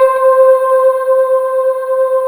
Index of /90_sSampleCDs/USB Soundscan vol.28 - Choir Acoustic & Synth [AKAI] 1CD/Partition D/24-THYLIVOX